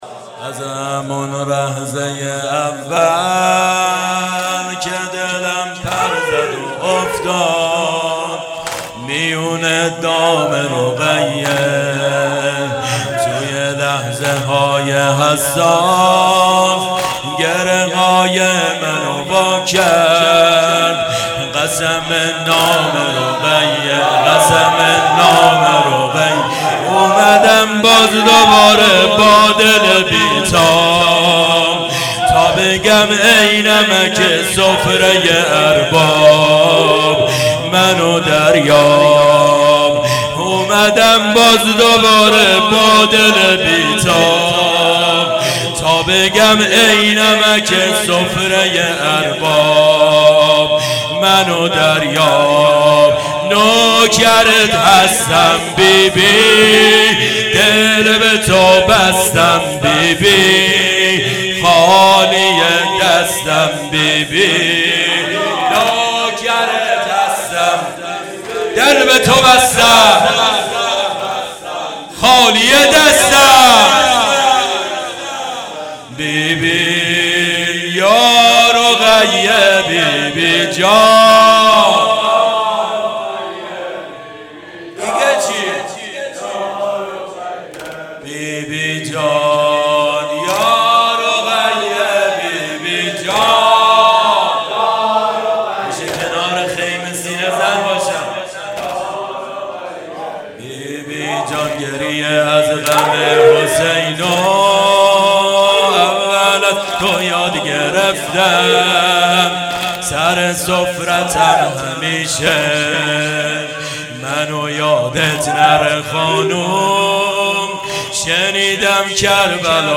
مکان:شهرک شهدا نبش پارک لاله هیئت علمدار سنقر